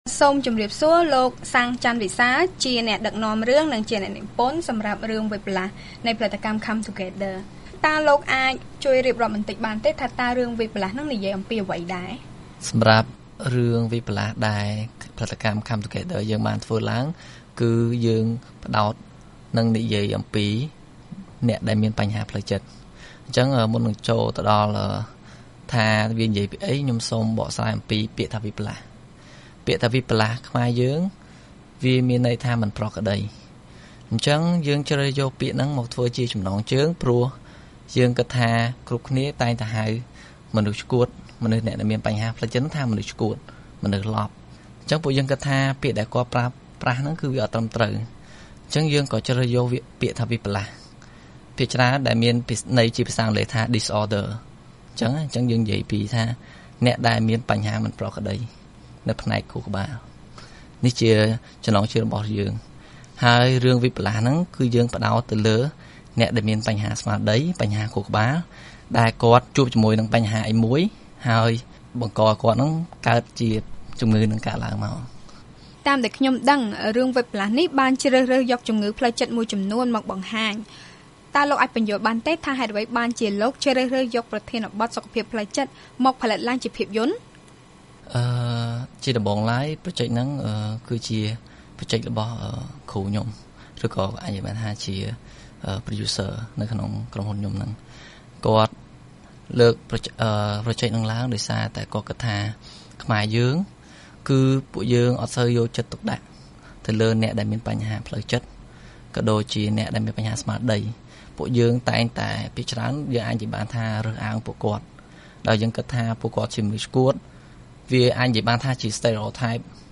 បទសម្ភាសន៍ VOA៖ ខ្សែភាពយន្ត «វិបល្លាស/រចនា» បង្កើនការយល់ដឹងពីបញ្ហាសុខភាពផ្លូវចិត្តនៅកម្ពុជា